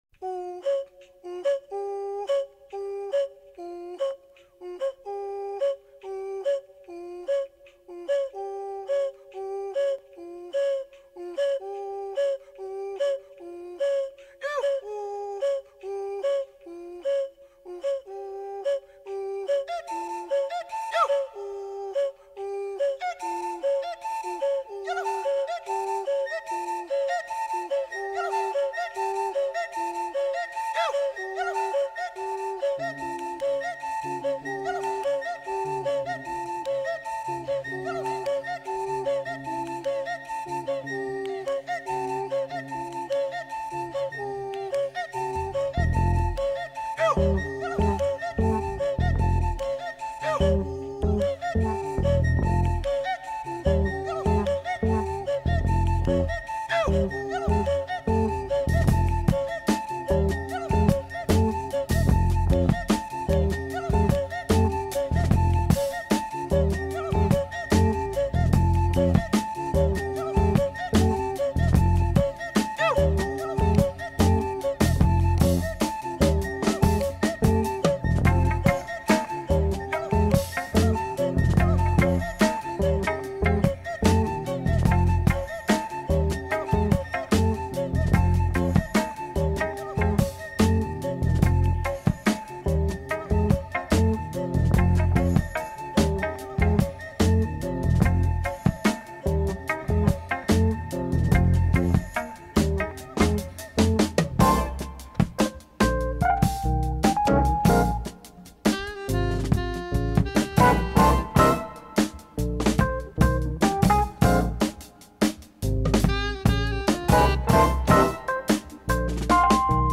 The listener is not addressed but an audience in the room is. -Listening for trauma.